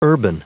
Prononciation du mot urban en anglais (fichier audio)
Prononciation du mot : urban